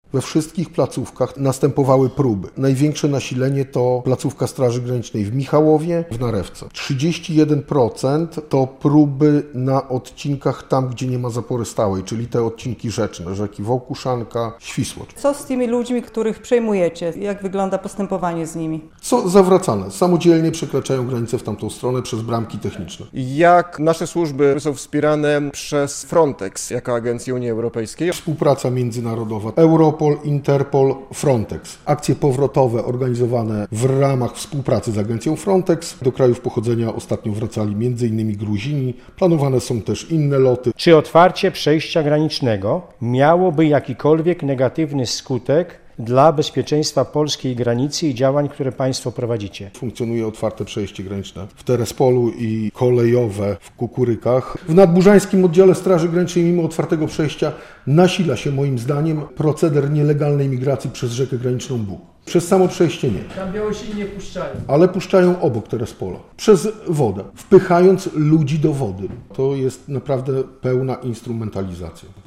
Sytuacja na granicy polsko-białoruskiej jednym z głównych tematów sesji - relacja